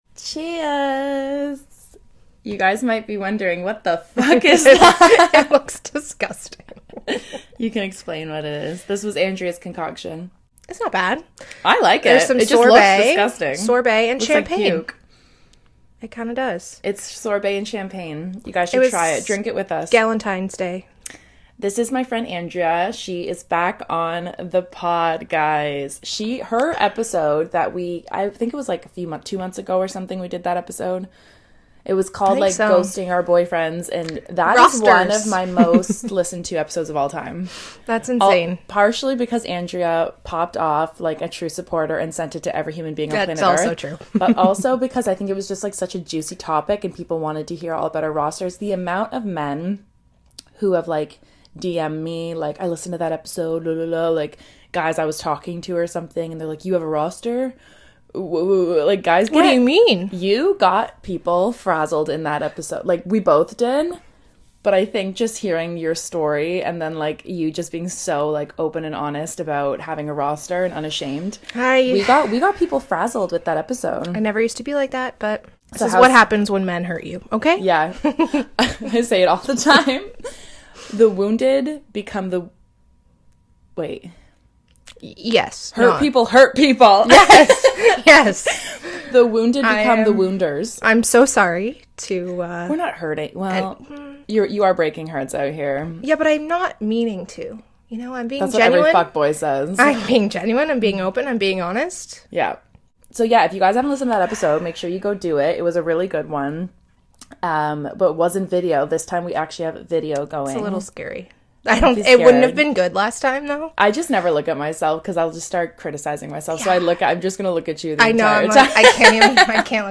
a candid conversation about the idea of “settling” and the complexities of modern dating.